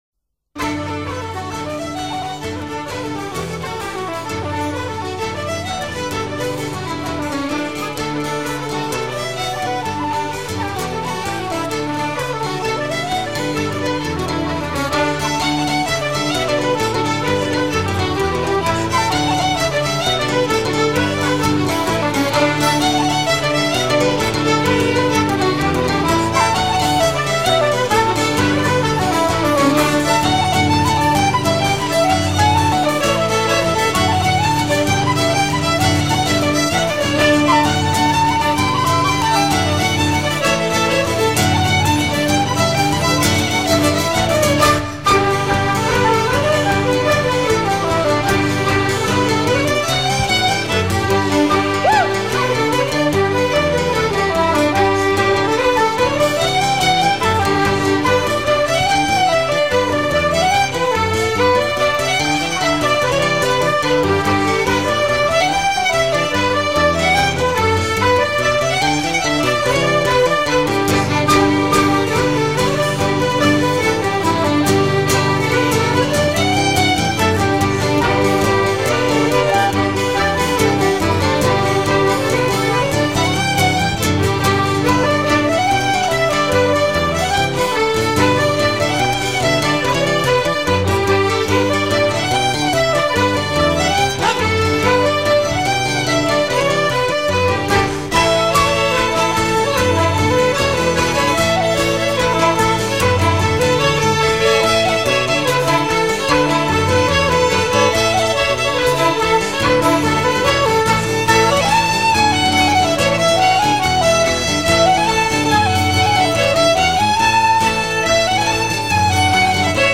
groupe qui fait vivre la musique irlandaise aux Etats-Unis
danse : jig ; danse : polka ; danse : reel ;